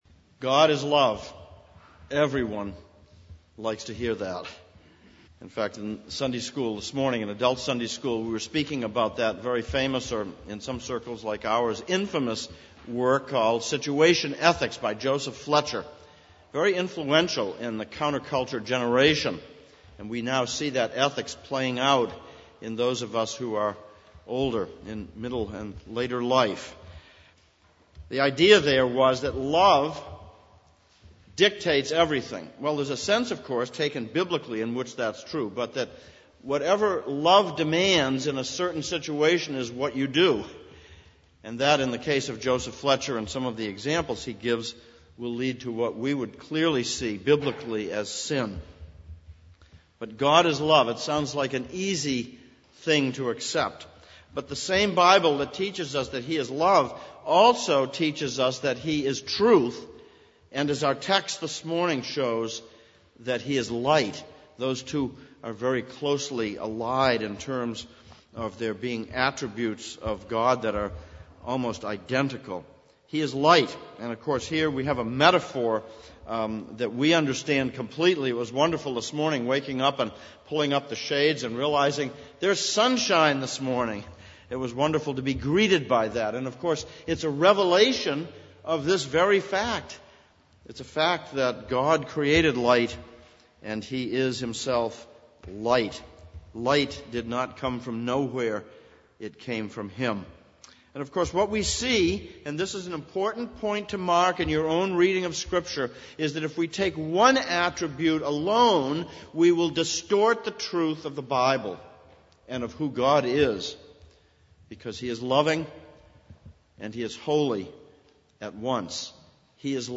Exposition on 1 John Passage: 1 John 1:1-10, Psalm 27:1-14 Service Type: Sunday Morning ver.. 5-10 « King of Kings 3.